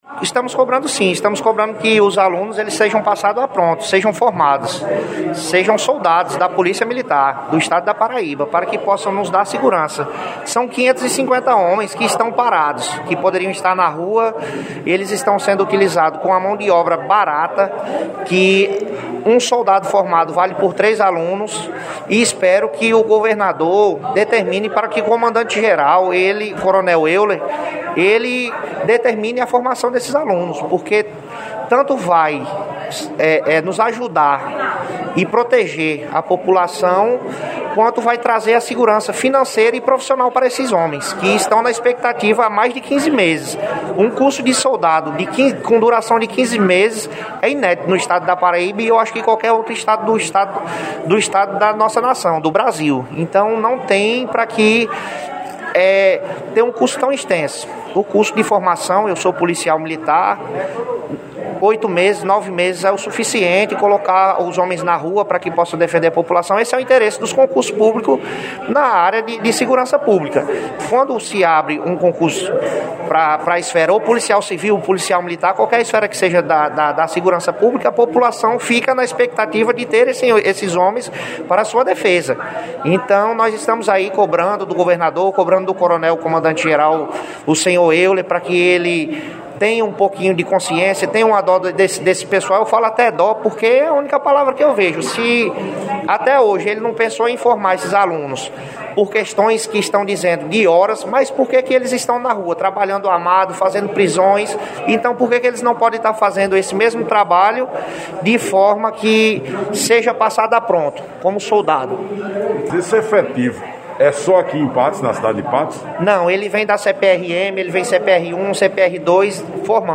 O vereador sargento Patrian (rede sustentabilidade) que esteve na sessão ordinária da Câmara Municipal de Patos nesta quinta-feira (11) utilizou o tempo que lhe é peculiar na Tribuna da casa Juvenal Lúcio de Sousa e apresentou aos demais pares seis importantes requerimentos que beneficiam principalmente a classe menos favorecida da sociedade.